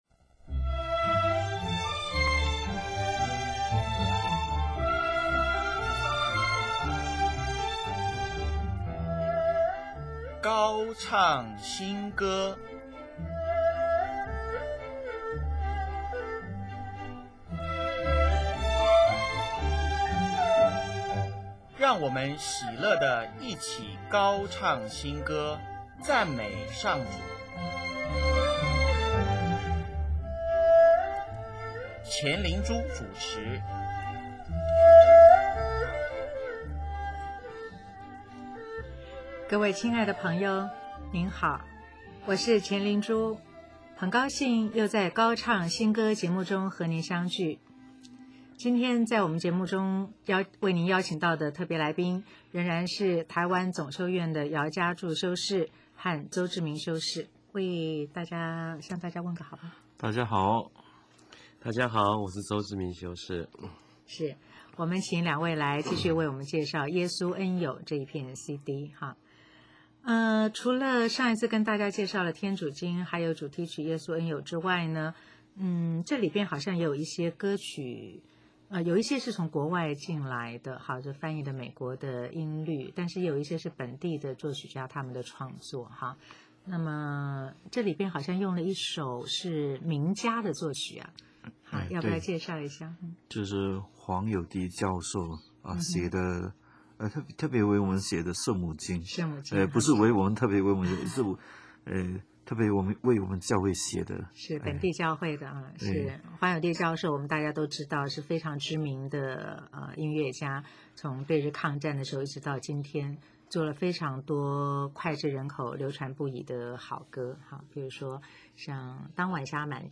本集播放：黄友棣教授为天主教会写的“圣母经”、江文也谱曲的“上主是我的牧者”、钢琴演奏曲“主永活在我心”。